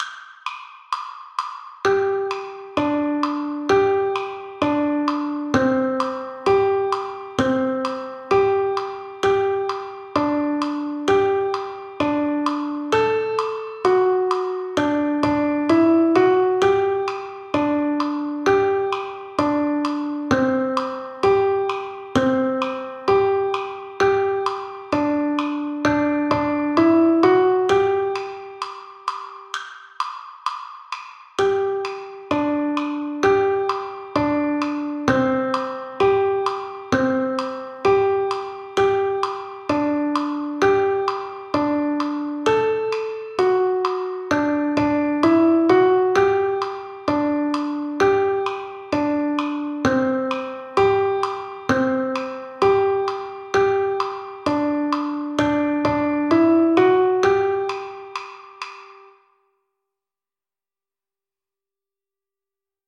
Canción tradicional de Estados Unidos.